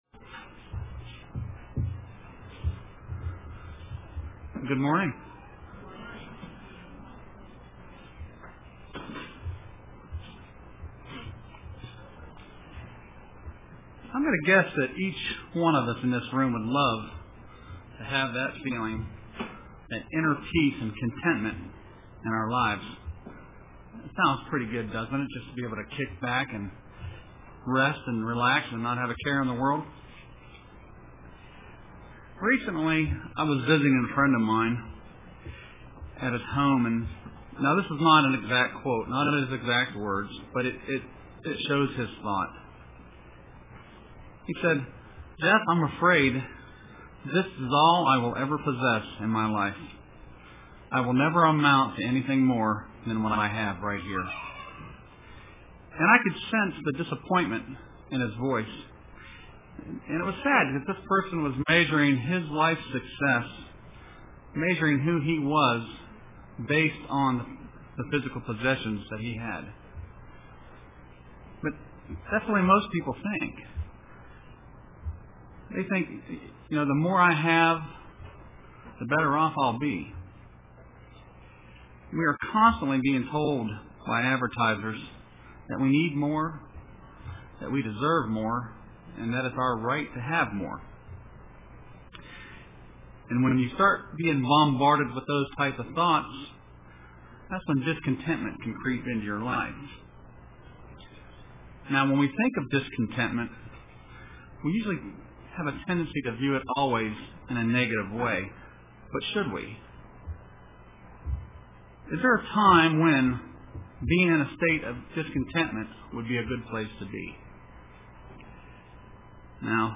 Print Contentment or Discontentment UCG Sermon Studying the bible?